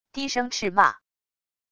低声斥骂wav音频